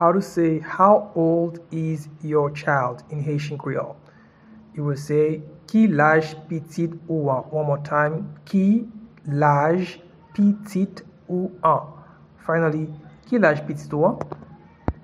Pronunciation and Transcript:
How-old-is-your-child-in-Haitian-Creole-Ki-laj-pitit-ou-an.mp3